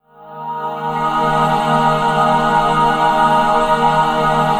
45 PAD 1  -R.wav